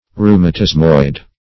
Search Result for " rheumatismoid" : The Collaborative International Dictionary of English v.0.48: Rheumatismoid \Rheu`ma*tis"moid\, a. [Rheumatism + -oid.]
rheumatismoid.mp3